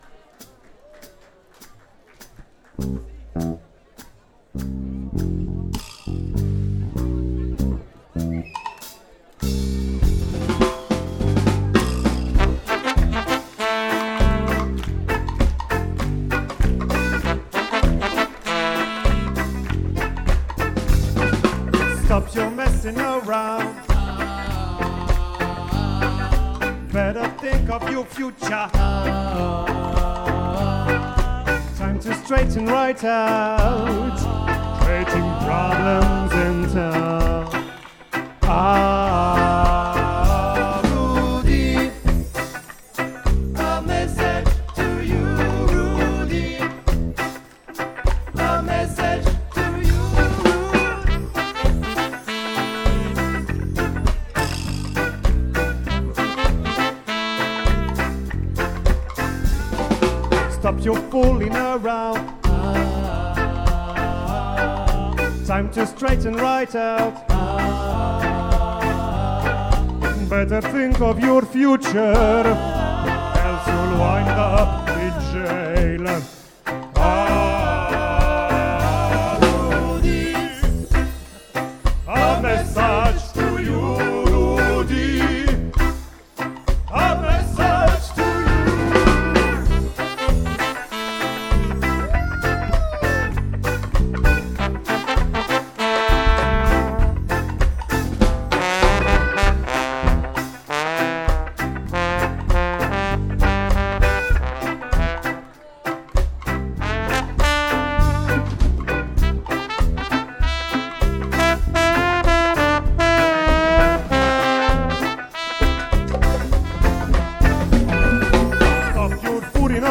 Ska- Raeggae-Soul
rocksteady